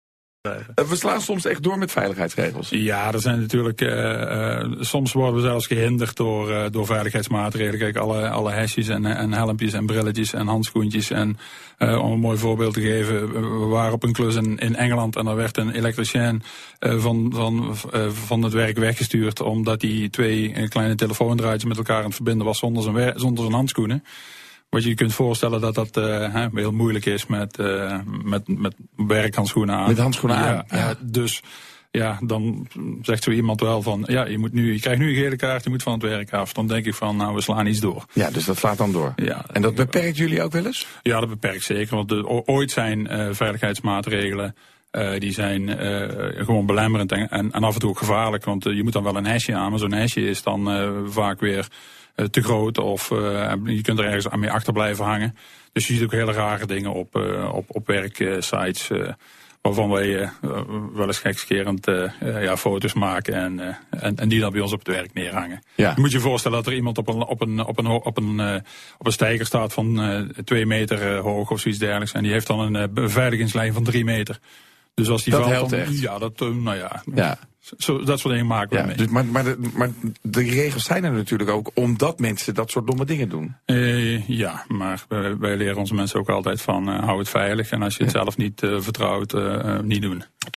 een interview op BNR Nieuwsradio.